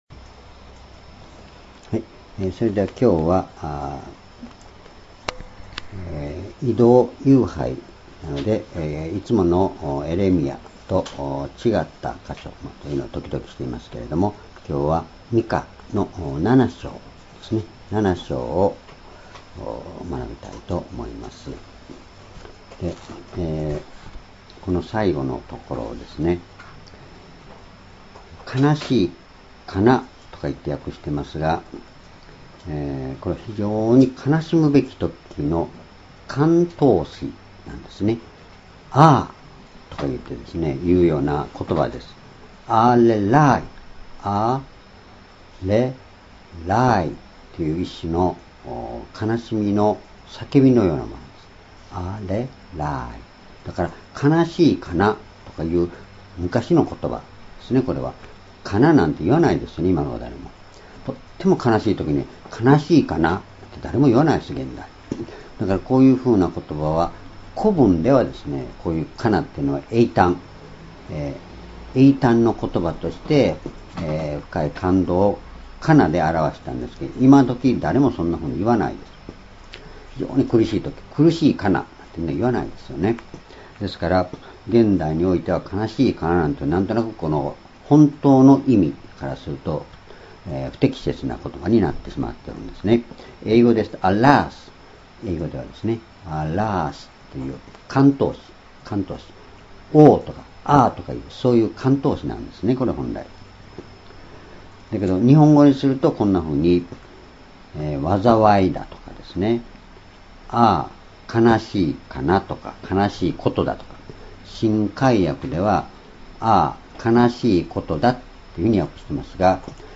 ２０１７年９月１６日（移動夕拝）
主日礼拝日時： 2017年9月26日 移動夕拝 聖書講話箇所 ミカ書7章1節-8節 「主こそ、わが光、」 ※視聴できない場合は をクリックしてください。